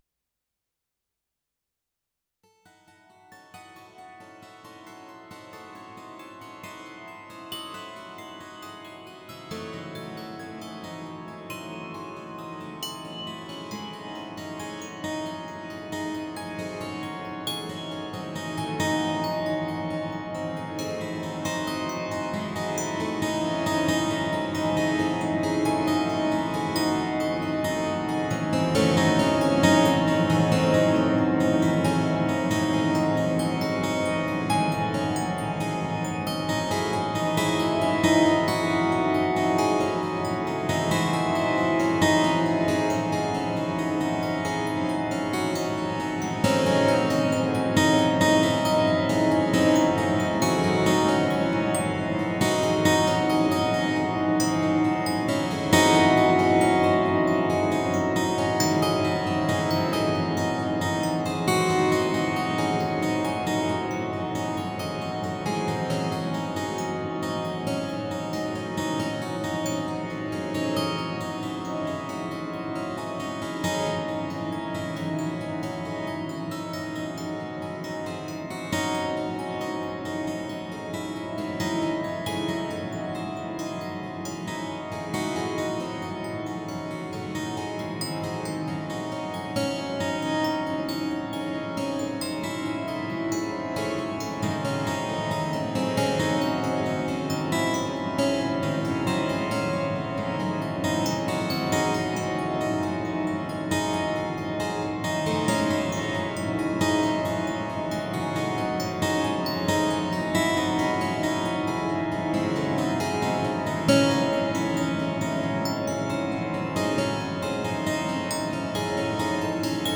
一聴して、何本ものアコースティックギターが、同時に即興的な分散和音オーケストレーションを行なっているように思えます。
また各々の音は空間を前後左右に、自由に飛び回ります。
ギター愛好家の方々にはもちろん、現代音楽、先端的テクノ、実験音楽をお好きな方々にもお薦めのアルバムです。